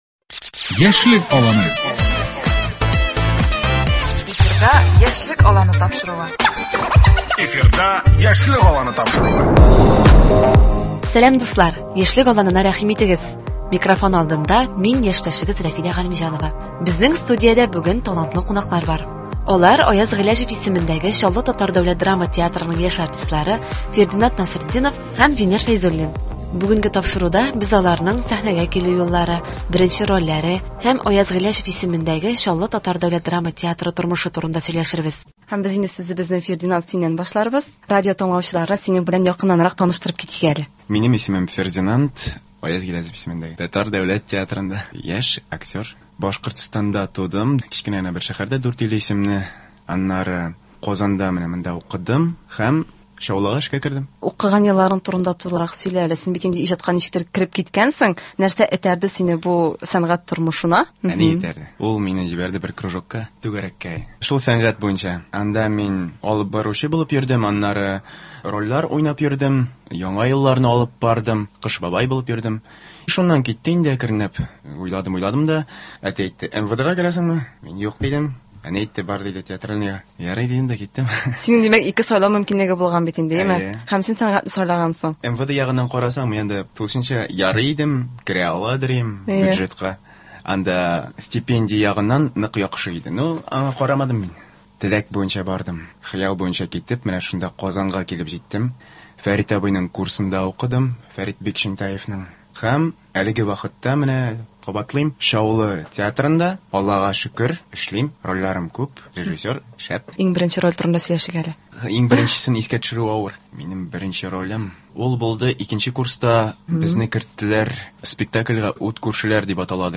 Безнең студиядә бүген талантлы кунаклар бар.